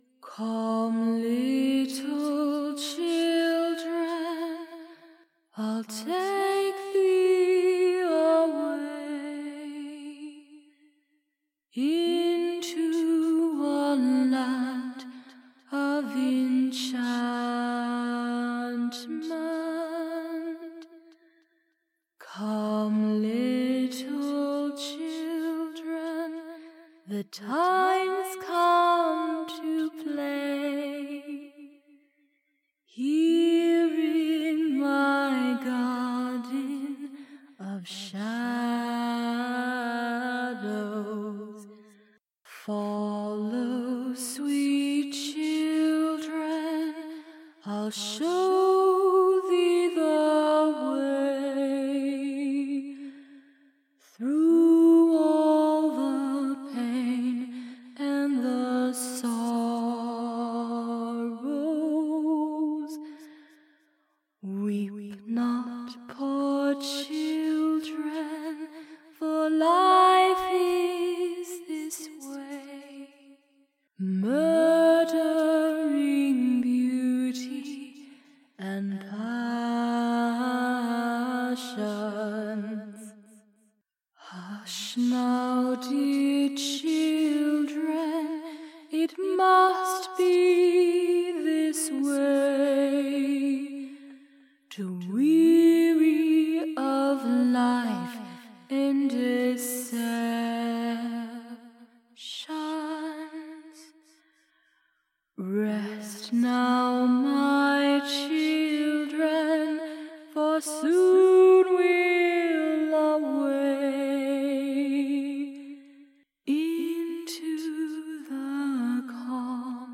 Singing
A Haunting Acapella Cover